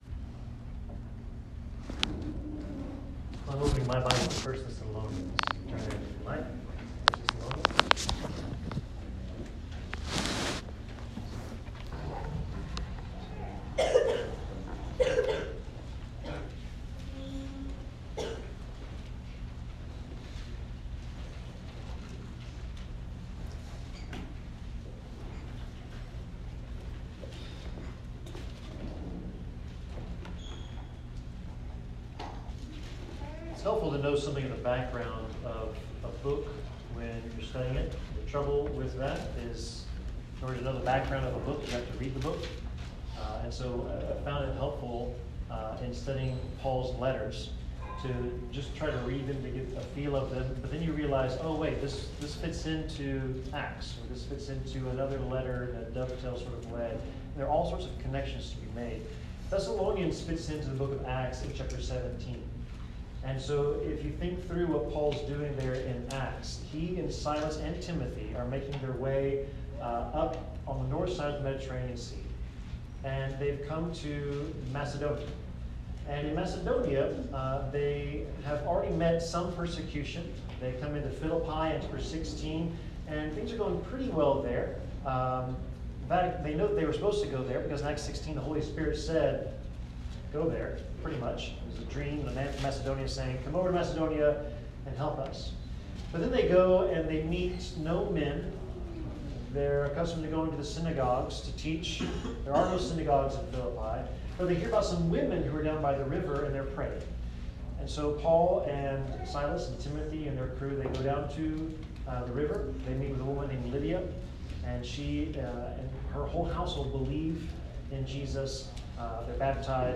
1 Thessalonians 5:12-24 Service Type: Sermon It can be tough to be a Christian.